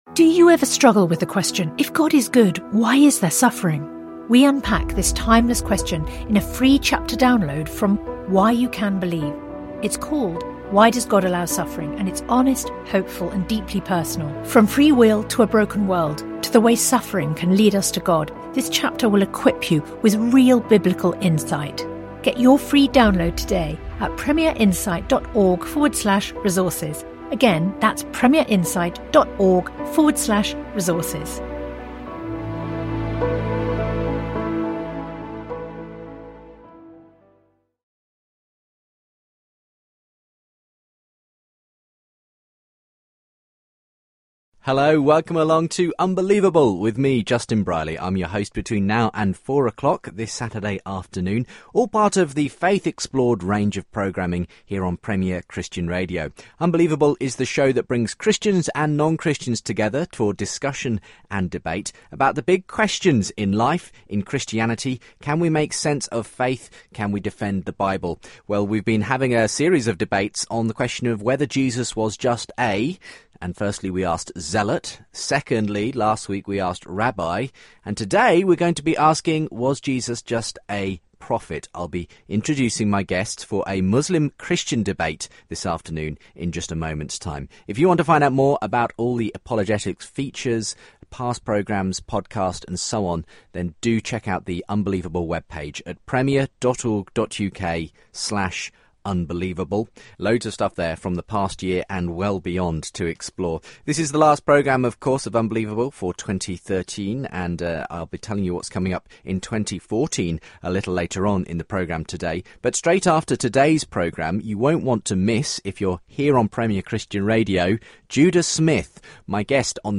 they debate the issues.